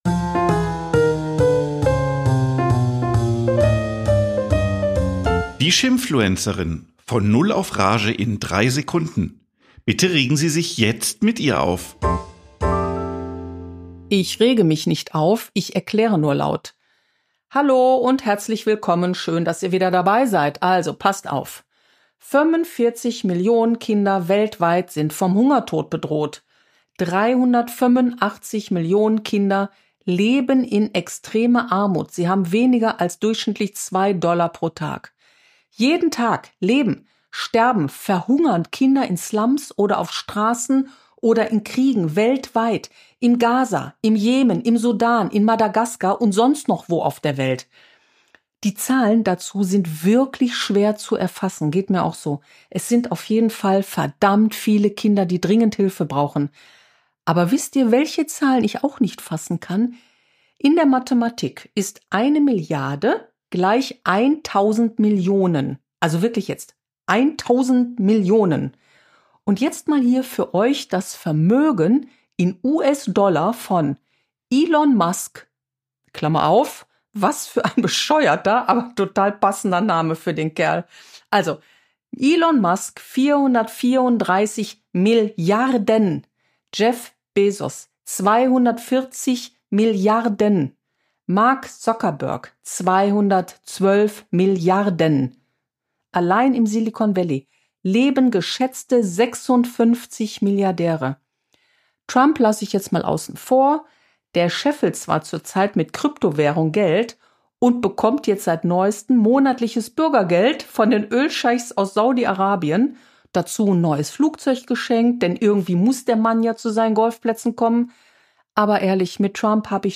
Eine Frau regt sich auf